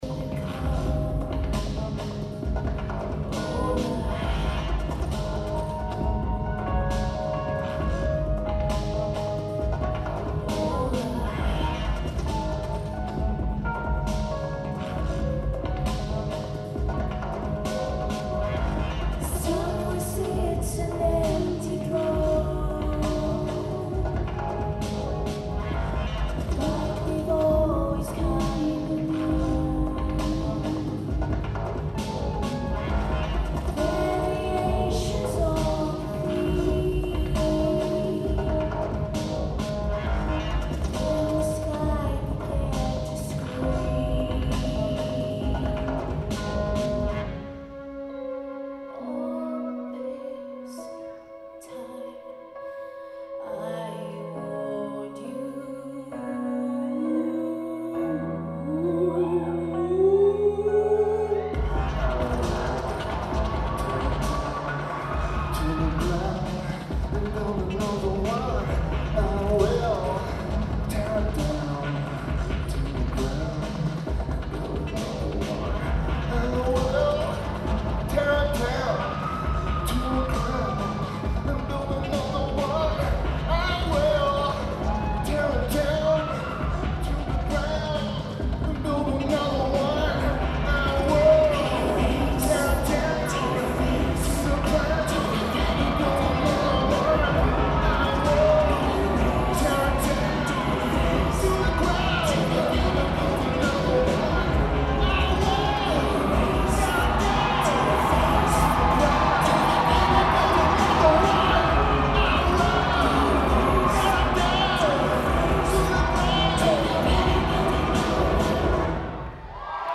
Fox Theater
Amazing tape.